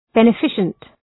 Shkrimi fonetik {bə’nefısənt}
beneficent.mp3